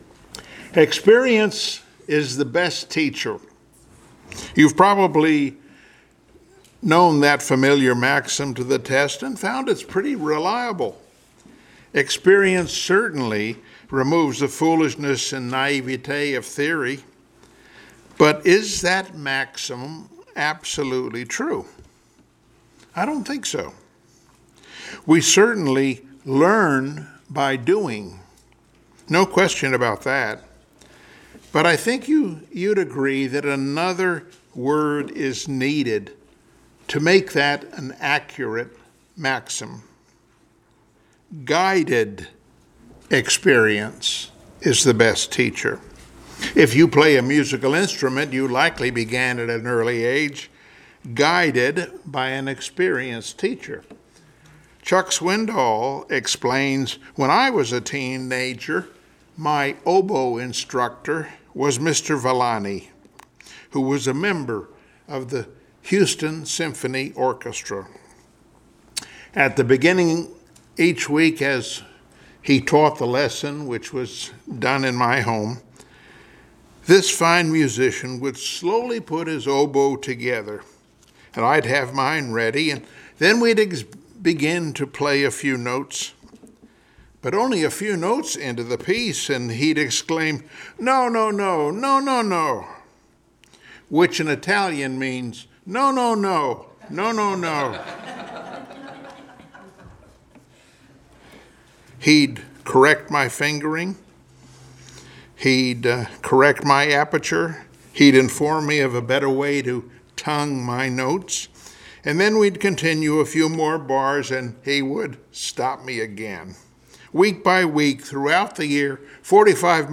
Passage: Mathew 16:22-23, Luke 22:31-34, 47-51 Service Type: Sunday Morning Worship